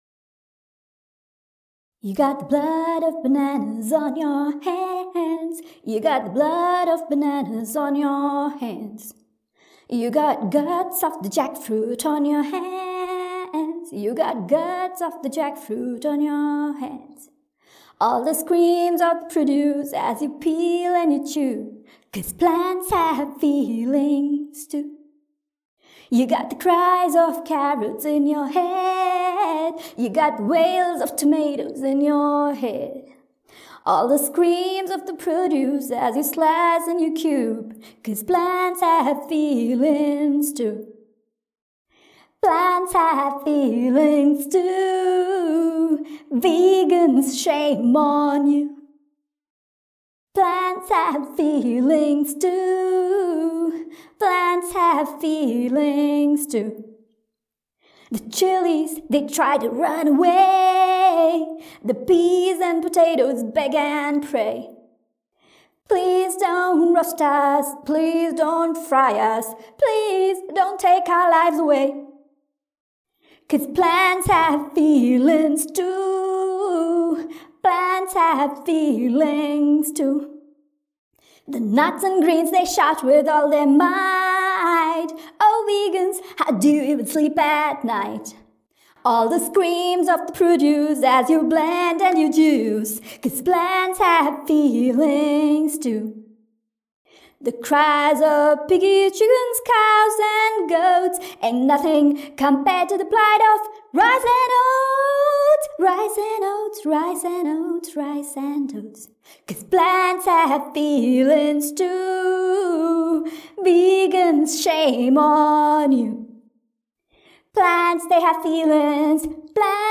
Original songs written and performed by me.
A satirical song about the very common last straw argument that vegans commonly face.